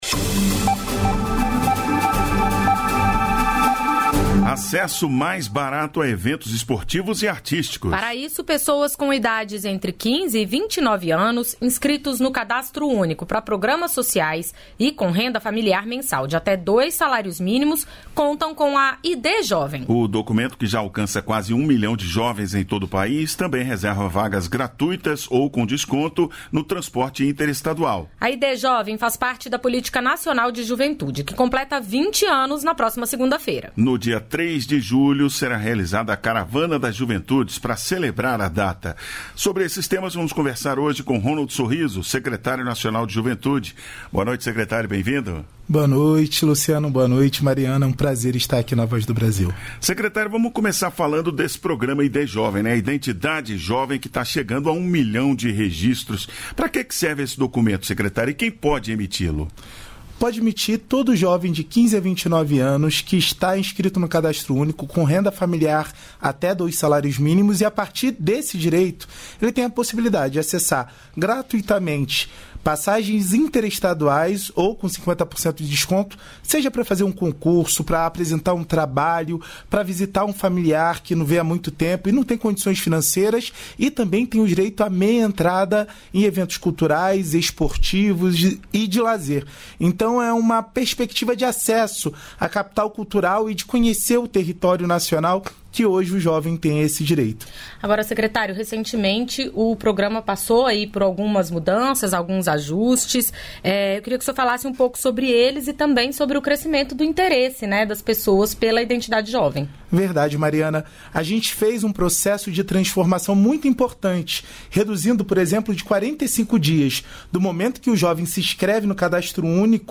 Entrevistas da Voz